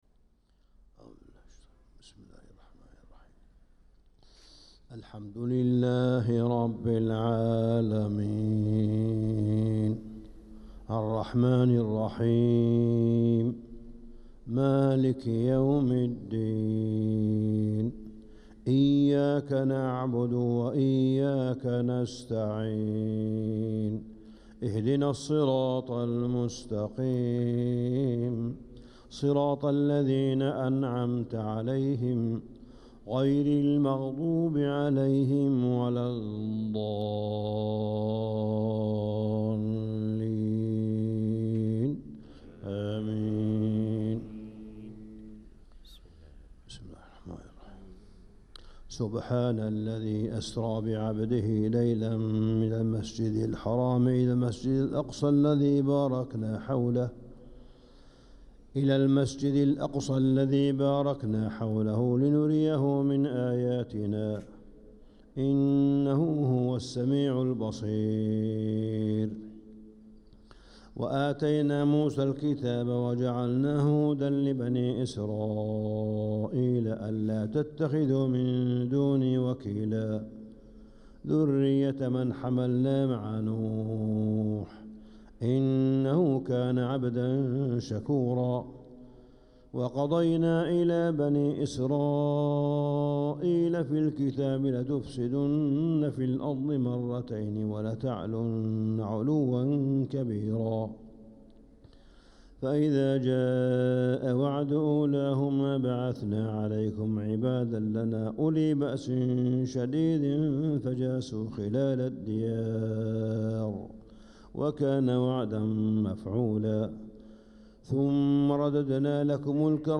صلاة الفجر للقارئ ياسر الدوسري 3 ربيع الأول 1446 هـ
تِلَاوَات الْحَرَمَيْن .